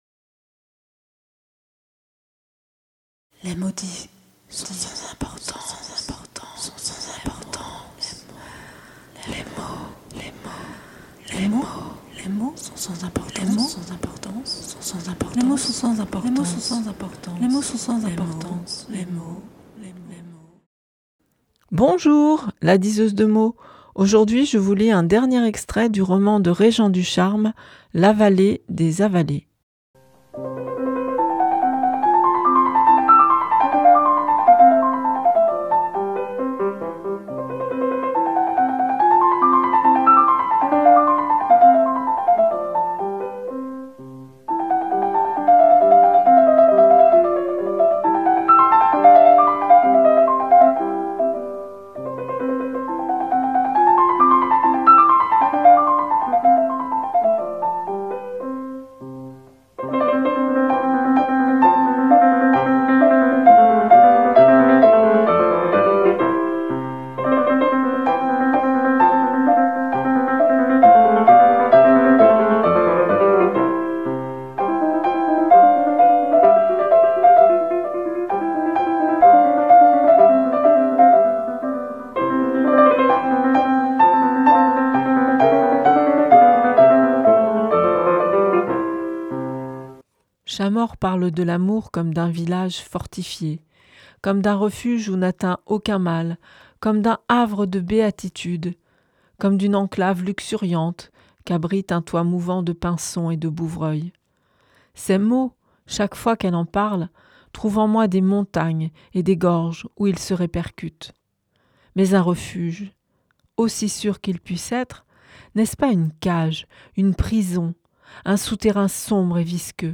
4è extrait du livre "L'avalée des avalés", de Réjean Ducharme